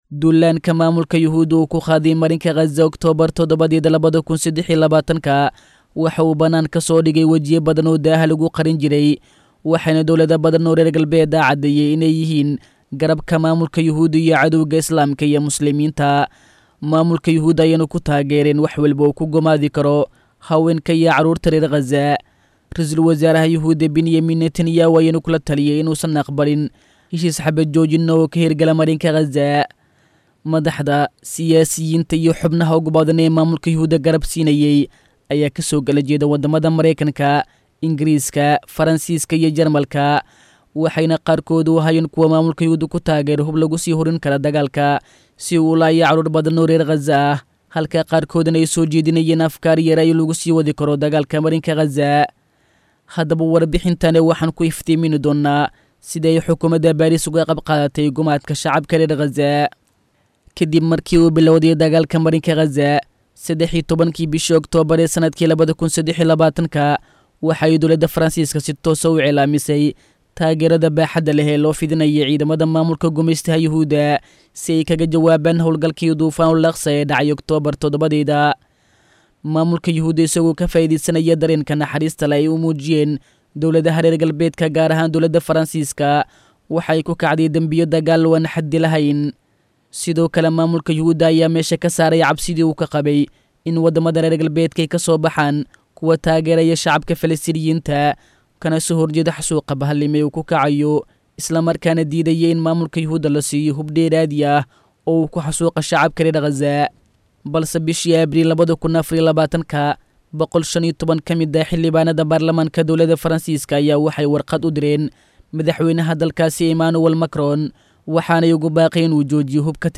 Kaalin Intee La’eg Ayuu Faransiisku ku Leeyahay Gumaadka ka Socda Qazza?[WARBIXIN]